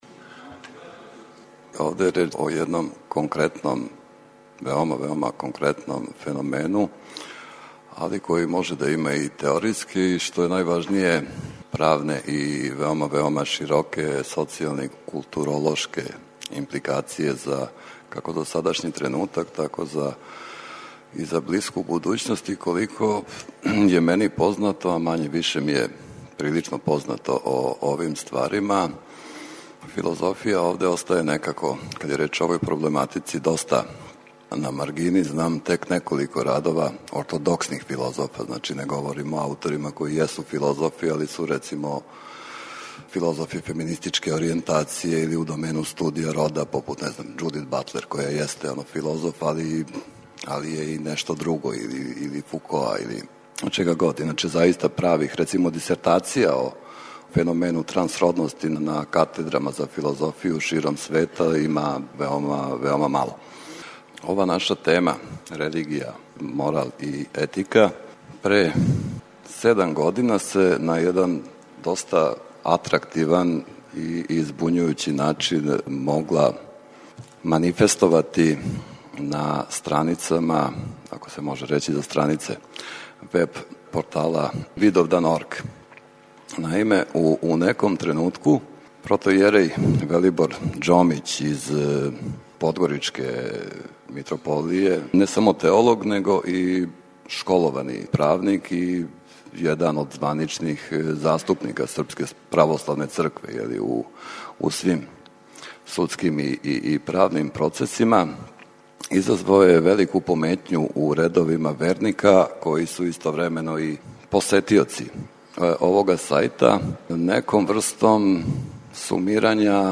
У циклусу 'Научни скупови' емитујемо прилоге са седамнаесте Филозофске школе 'Felix Romuliana' у Зајечару. Општи назив скупа гласио је 'Етика, морал, религија'.